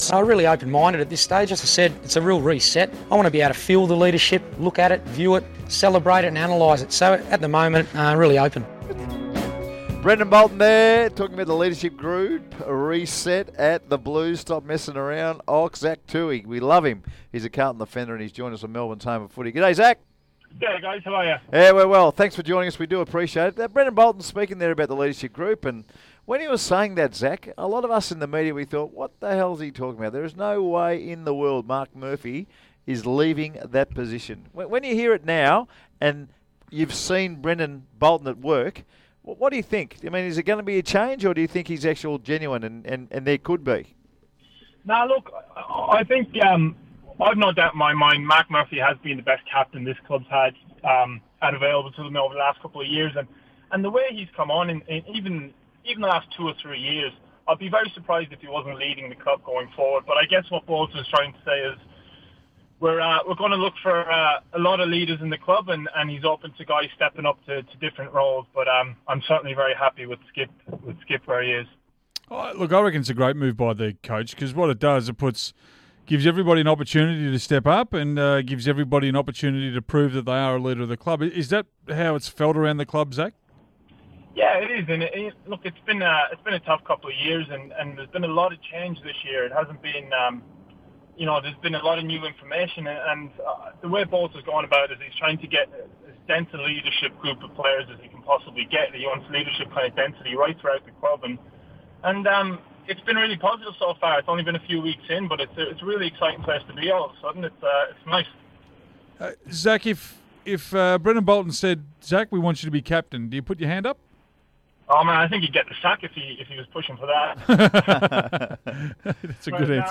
Carlton defender Zach Tuohy speaks to SEN 1116 ahead of the Blues' pre-season camp at the Gold Coast.